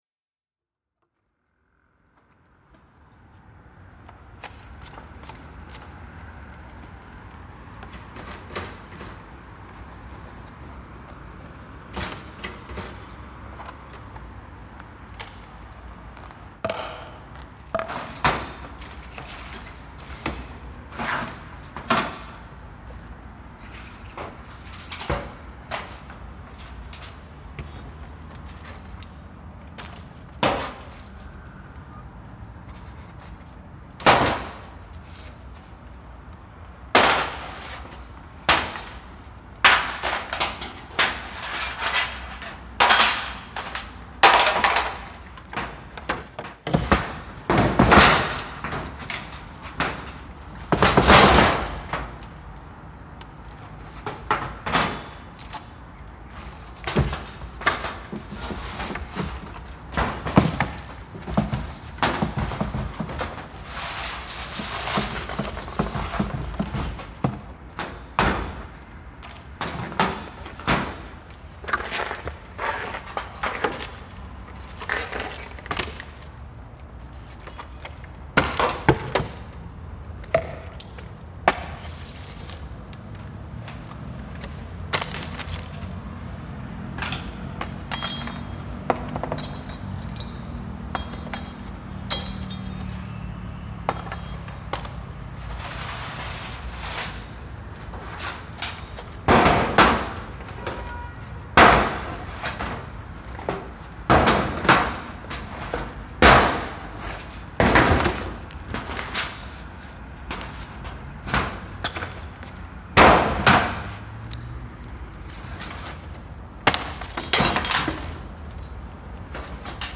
The grotesque communist-era buildings are one of the main attractions in Tirana and inspire me to go looking for new intriguing sounds and exquisite reverberation textures.
The pyramid was primarily built as a museum devoted to Enver Hohxa but after communism ended, it became forgotten. The soundscape highlights a soundwalk around the pyramid, mainly capturing the sounds of debris and different materials that reflect the state in which the pyramid was left or in its current vandalized state.